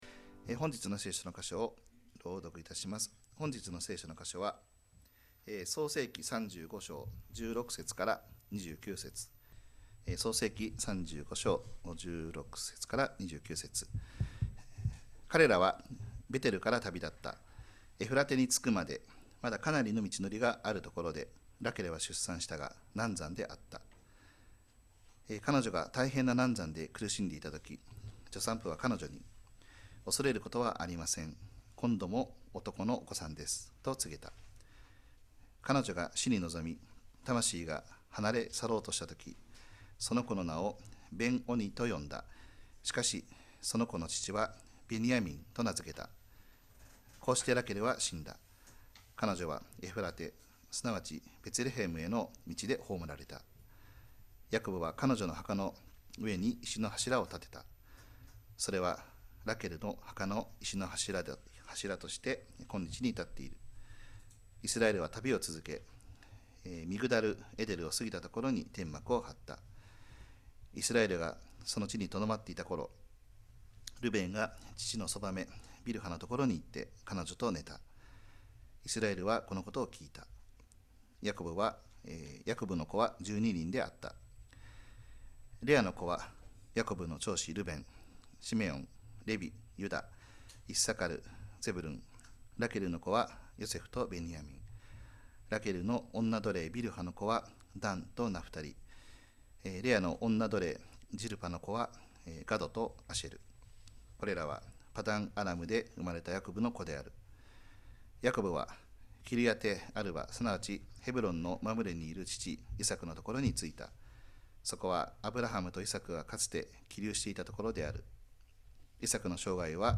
2025年3月2日礼拝 説教 「信じて、めでたしめでたし？」
礼拝式順